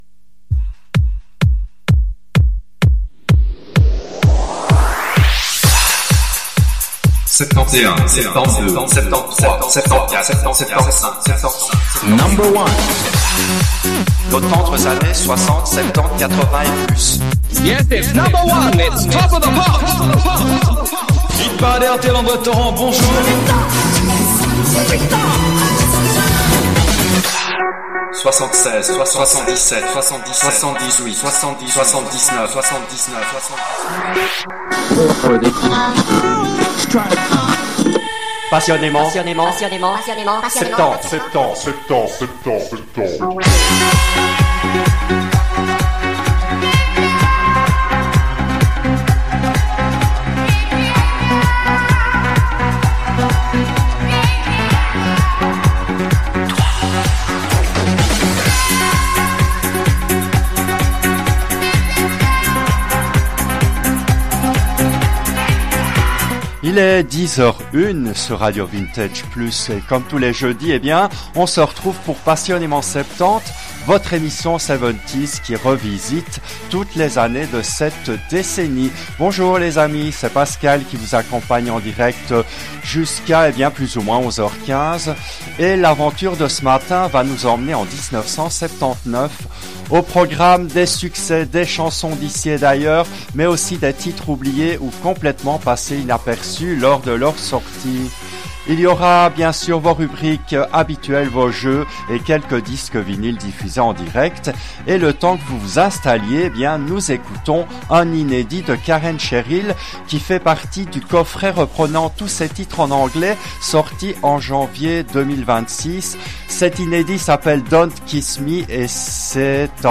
Cette émission a été diffusée en direct le jeudi 28 mars 2024 à 10h depuis les studios belges de RADIO RV+.